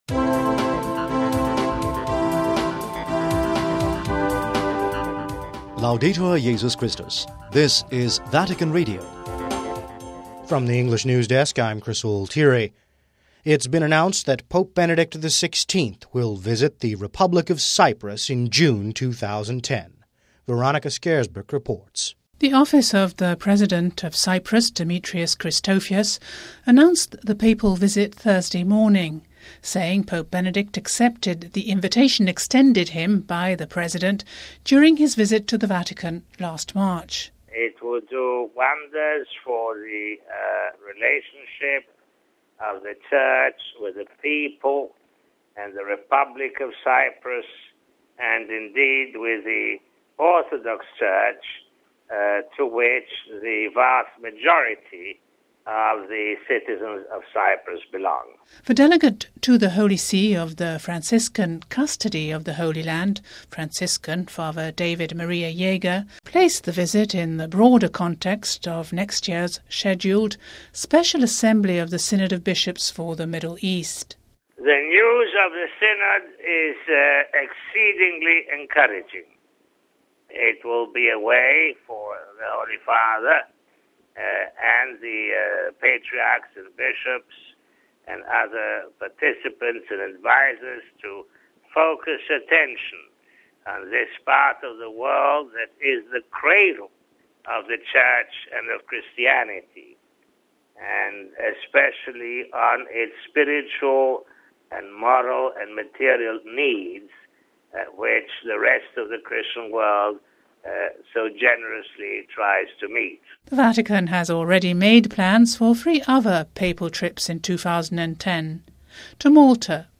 We have this report: RealAudio